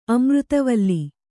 ♪ amřtavalli